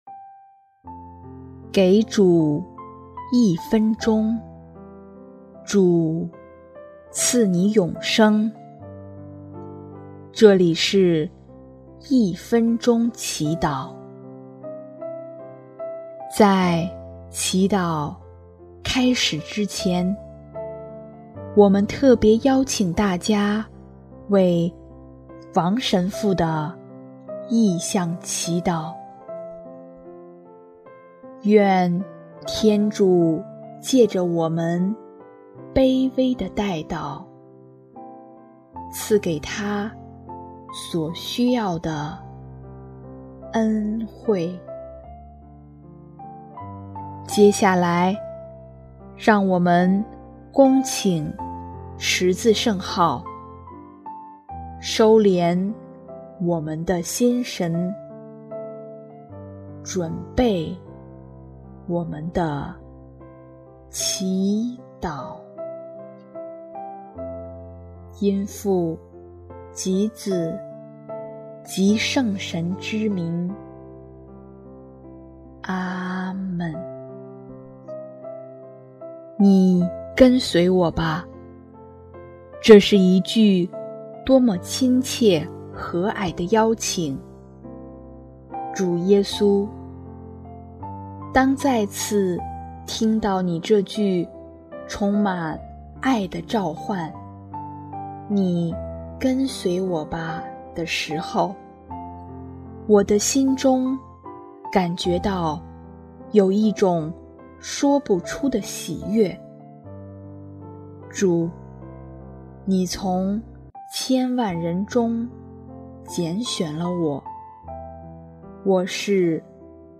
【一分钟祈祷】| 5月26日 蒙召，是选择度爱的生命